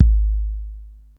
T4_Kik3.wav